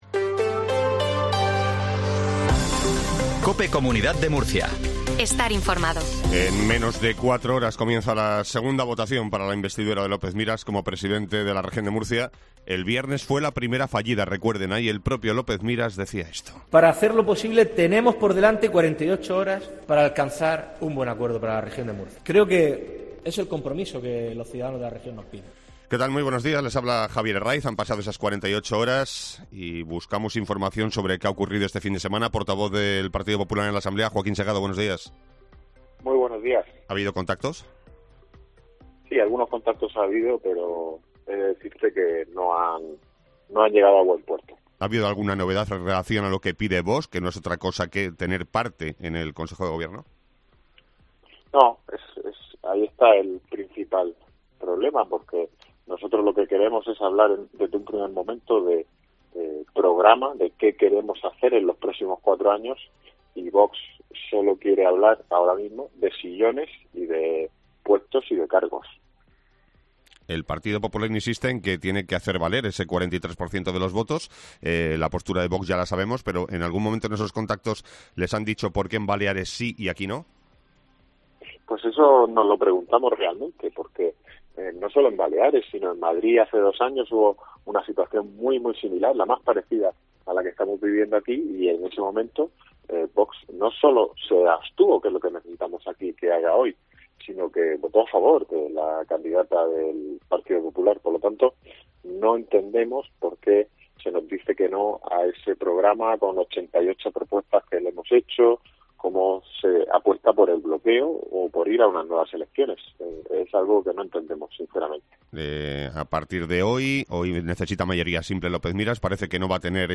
INFORMATIVO MATINAL REGION DE MURCIA 0720